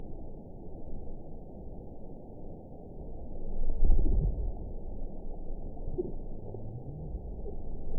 event 920765 date 04/07/24 time 22:40:25 GMT (1 year ago) score 8.01 location TSS-AB07 detected by nrw target species NRW annotations +NRW Spectrogram: Frequency (kHz) vs. Time (s) audio not available .wav